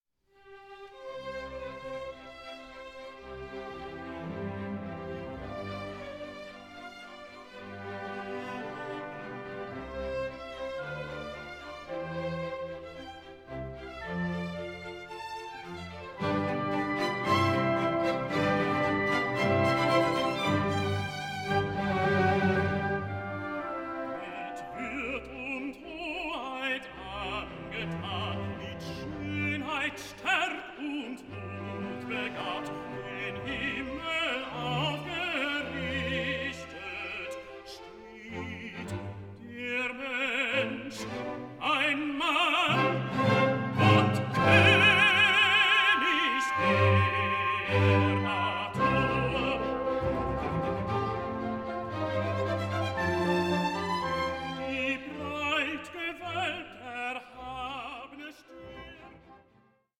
Classical transparency and Romantic drive